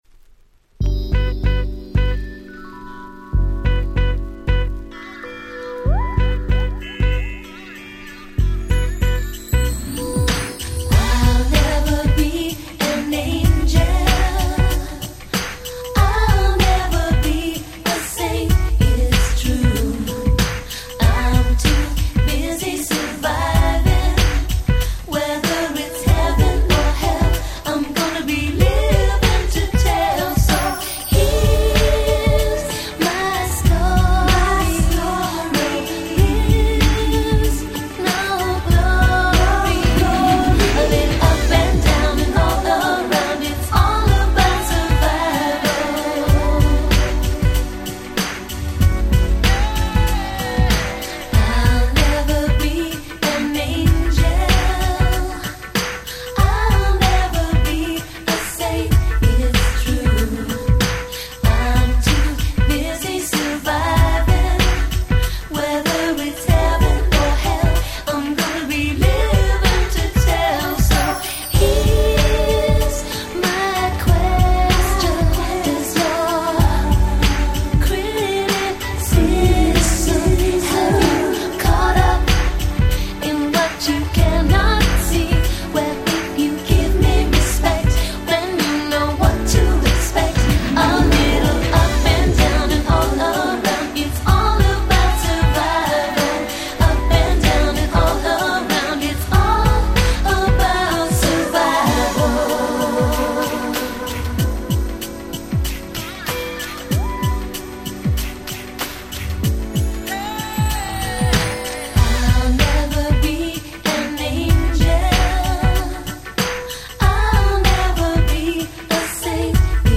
イントロから大人な雰囲気ムンムンなめちゃくちゃ良いR&Bチューンでオススメです！